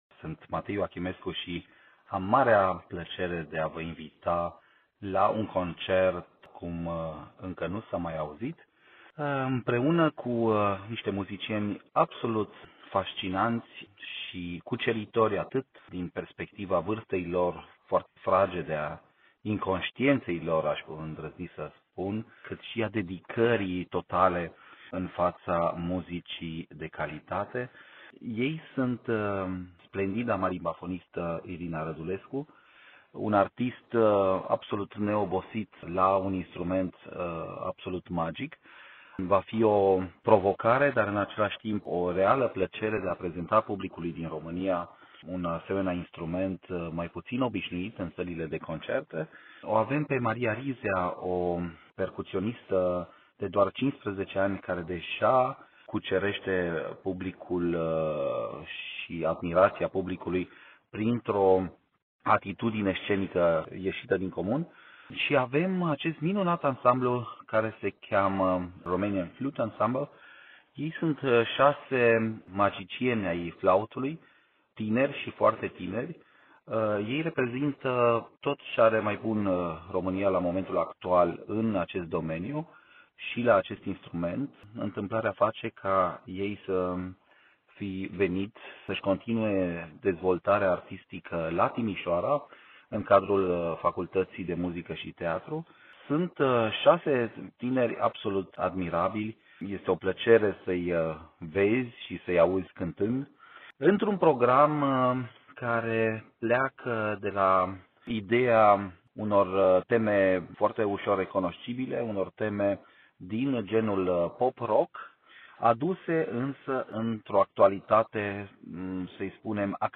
Interviuri cu protagoniștii proiectului - Radio România Timișoara